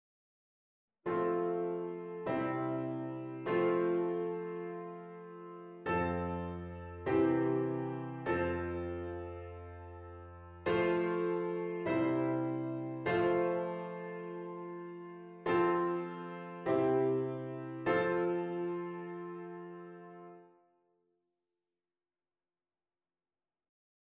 verbinding I - V - I (harmonische verbinding)